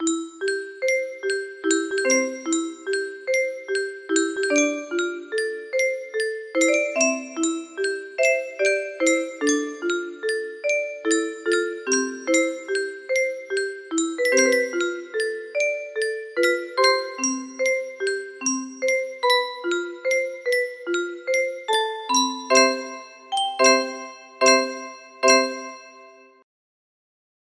pt2 music box melody